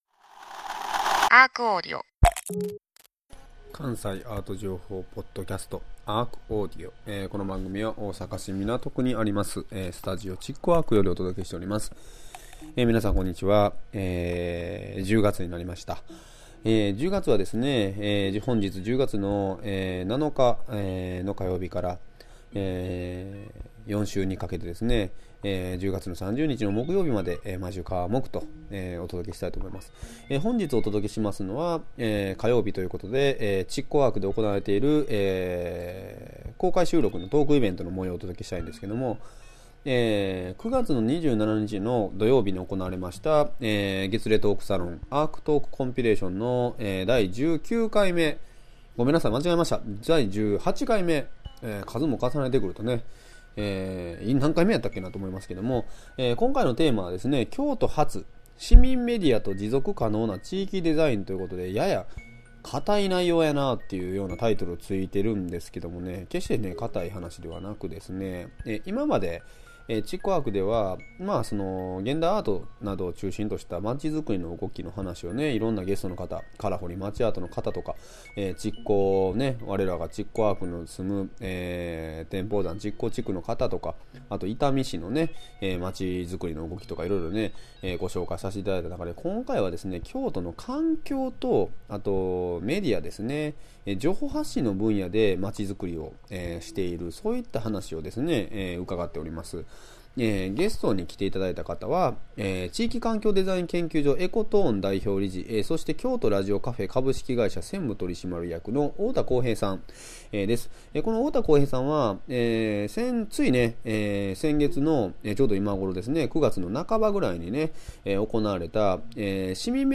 毎週火曜日は築港ARCにて毎月開催されるアートと社会を繋ぐトークイベント「ARCトークコンピレーション」の模様を全4回に分けてお届けします。